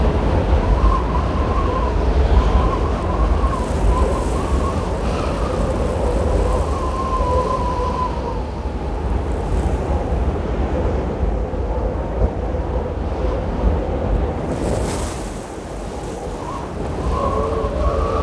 asteroidsurface.wav